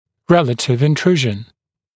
[‘relətɪv ɪn’truːʒn][‘рэлэтив ин’тру:жн]относительная интрузия (осуществляется за счет удержания моляров в период роста)